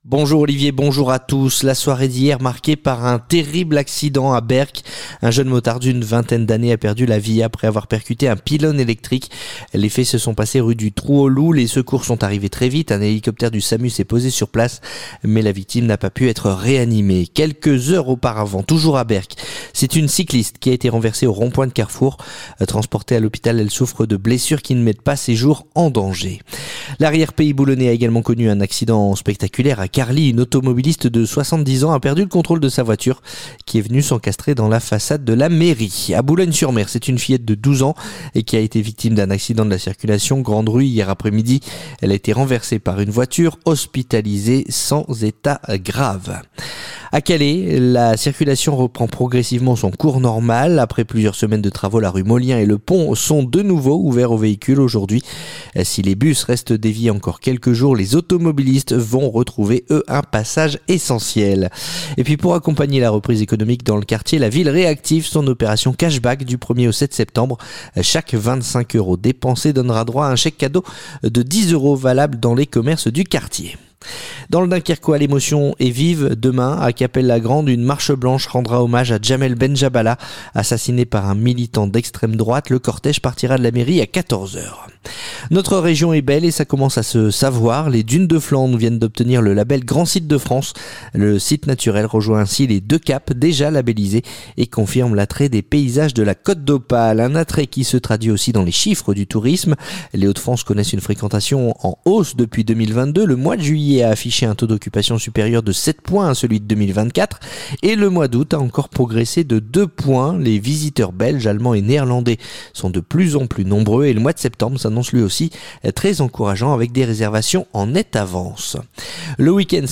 Le journal du samedi 30 août